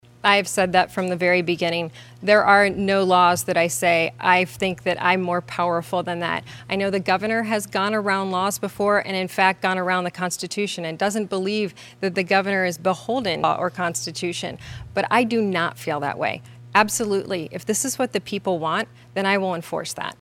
The debate brought the two contenders together for hour-long event at Oakland University.
Dixon countered.